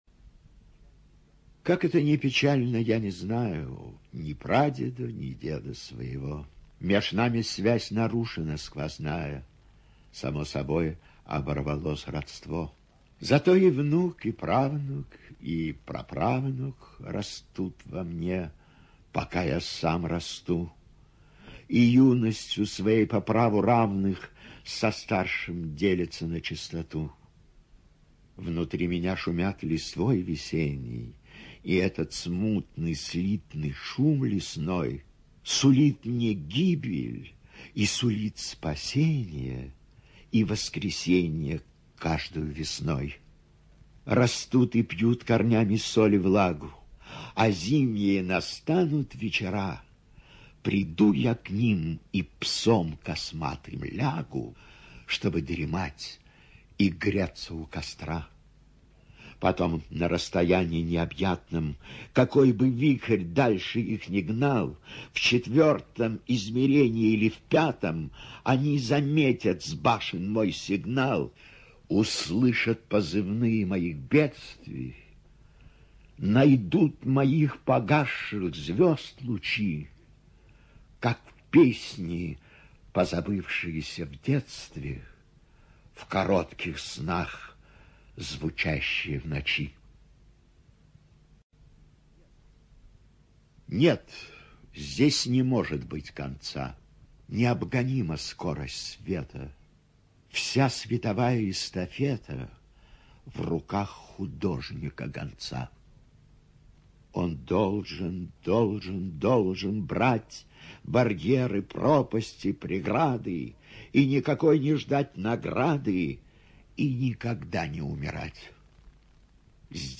ЧитаетЯкут В.
ЖанрПоэзия